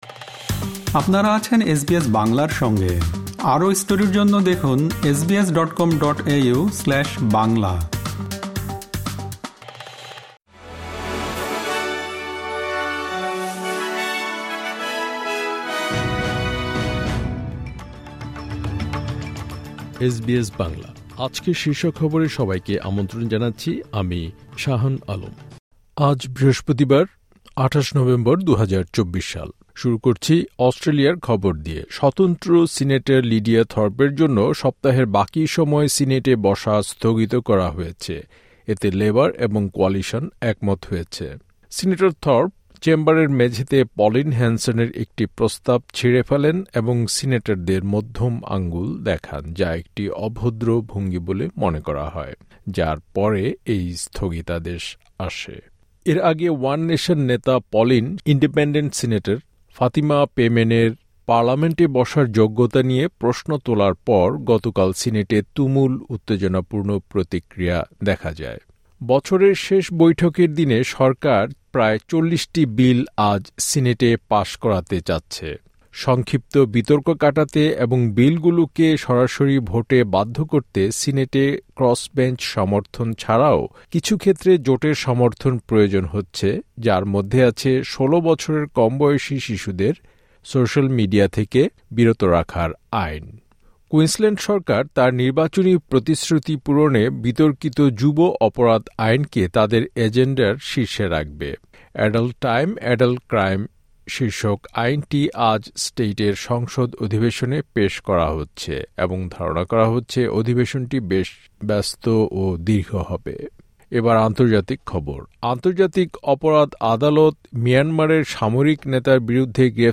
আজকের শীর্ষ খবর স্বতন্ত্র সিনেটর লিডিয়া থর্পের জন্য সপ্তাহের বাকি সময় সিনেটে বসা স্থগিত করা হয়েছে, এতে লেবার এবং কোয়ালিশন একমত হয়েছে।